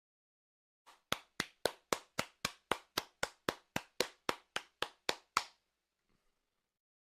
Clapping Free High Quality Effect